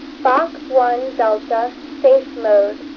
Fox-1D Voice Beacon
Here is a recording of AMSAT's latest satellite, Fox-1D, now AO-92 sending it's Voice Beacon about 24 hours after its launch. Recorded on an easterly pass from Fullerton, California. In case you can't tell, she is saying "Fox One Delta Safe Mode."
fox-1d-voice-beacon.mp3